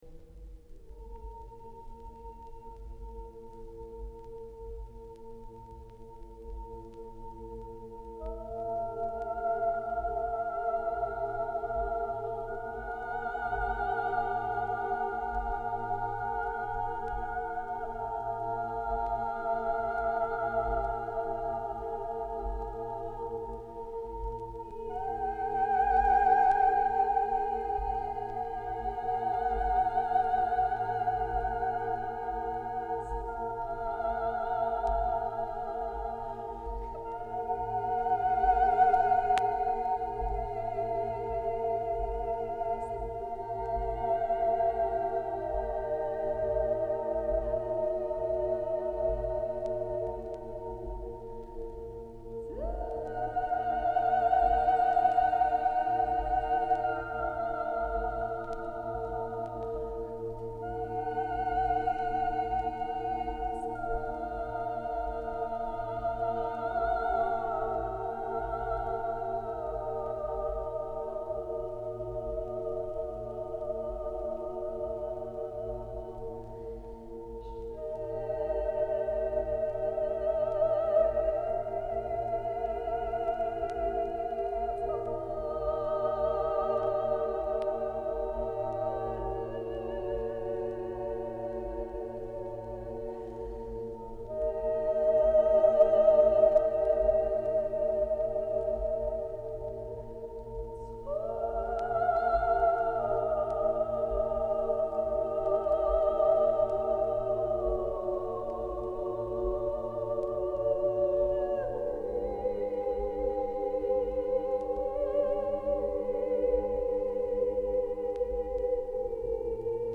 Christmas carols
Keywords: ქართული ხალხური სიმღერა